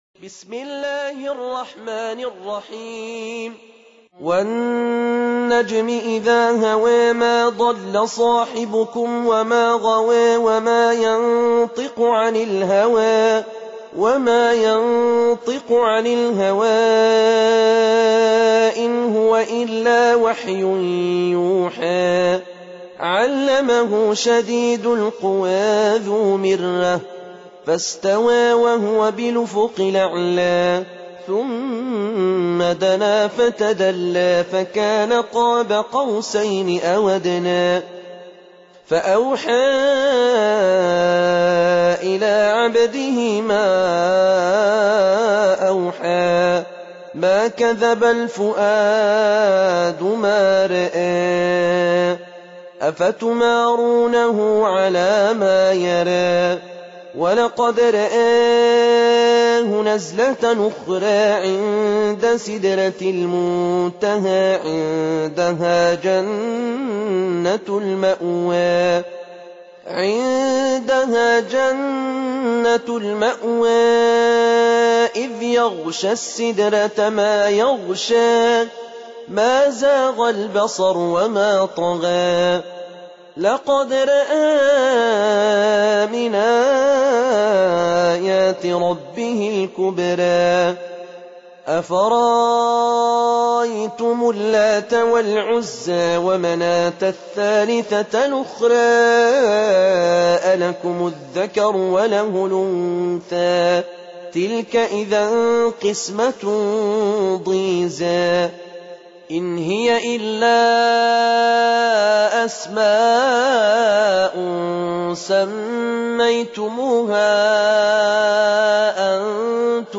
Surah Repeating تكرار السورة Download Surah حمّل السورة Reciting Murattalah Audio for 53. Surah An-Najm سورة النجم N.B *Surah Includes Al-Basmalah Reciters Sequents تتابع التلاوات Reciters Repeats تكرار التلاوات